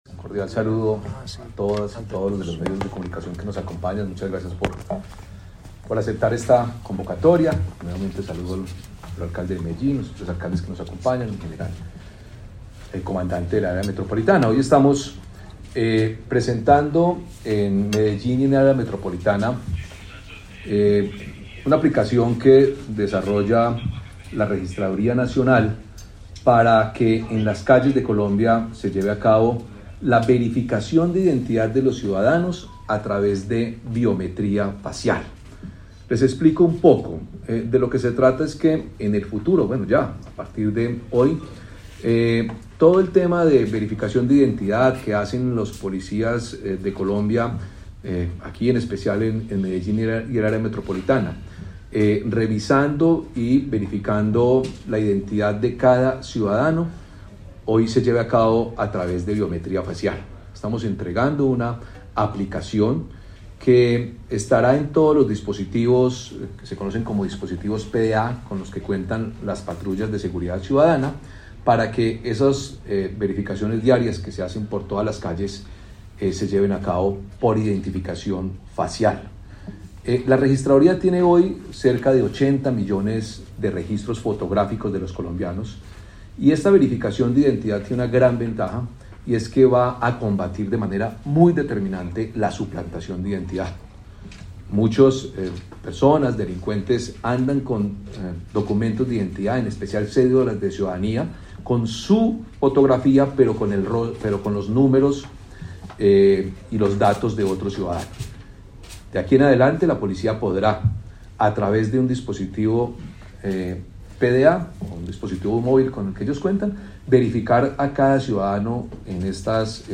Palabras de Hernán Penagos, registrador Nacional del Estado Civil